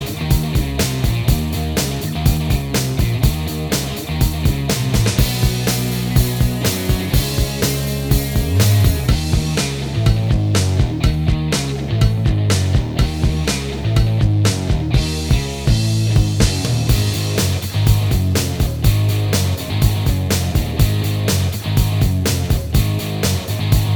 Minus Lead Guitar Rock 4:25 Buy £1.50